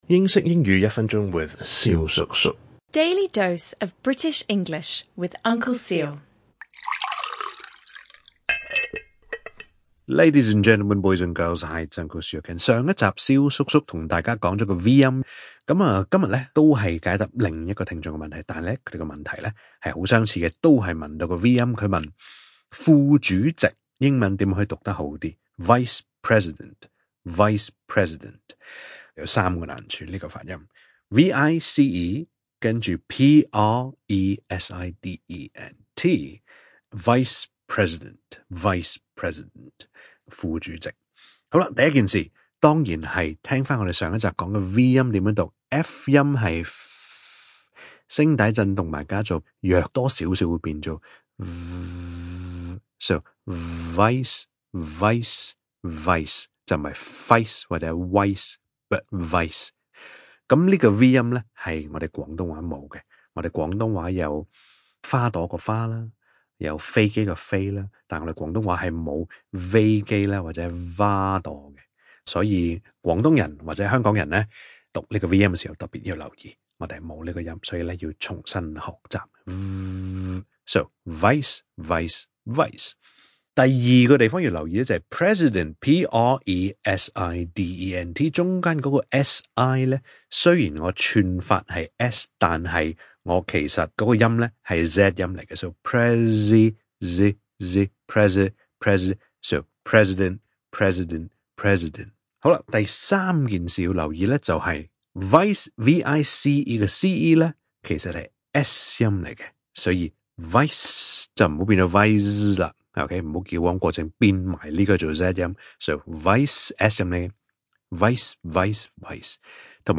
EP 1448 - 發音練習：And (2) – 英式英語一分鐘